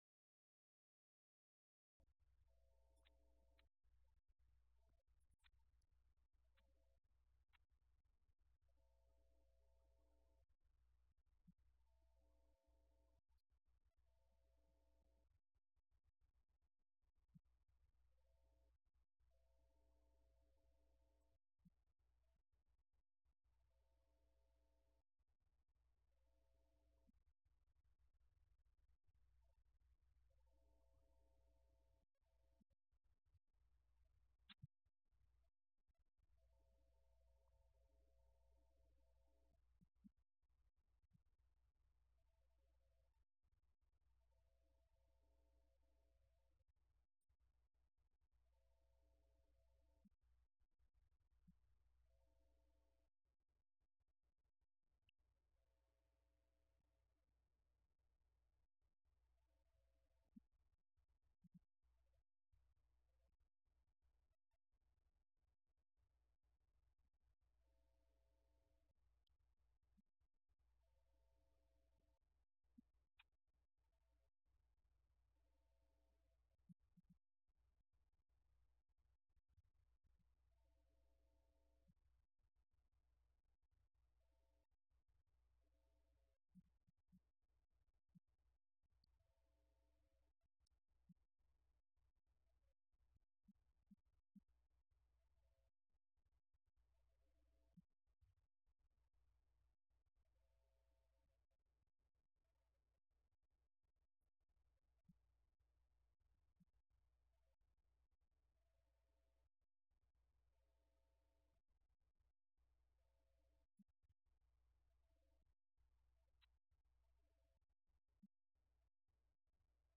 Event: 17th Annual Schertz Lectures Theme/Title: Studies in Job
this lecture